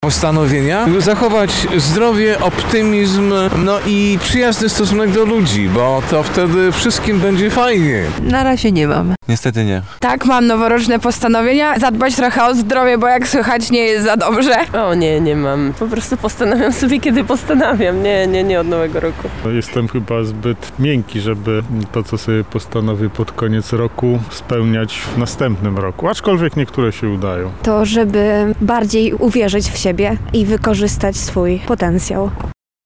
Zapytaliśmy mieszkańców Lublina, czy mają noworoczne postanowienia.
SONDA